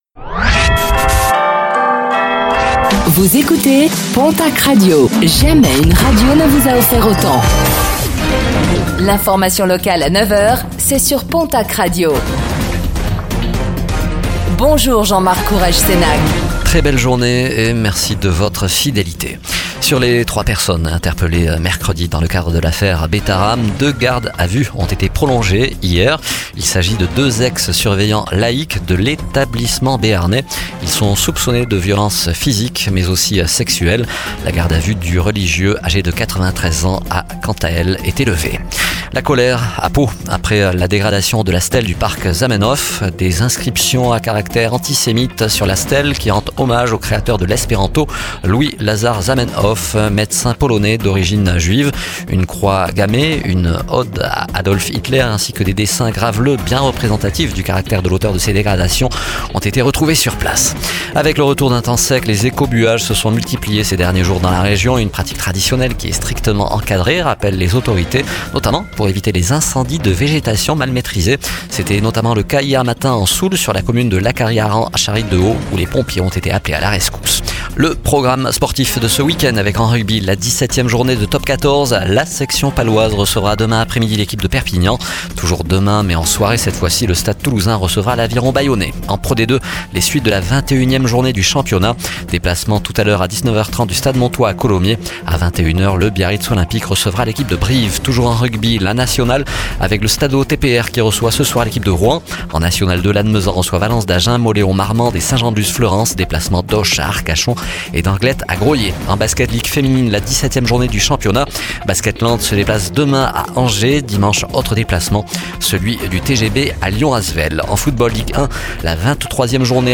Réécoutez le flash d'information locale de ce vendredi 21 février 2025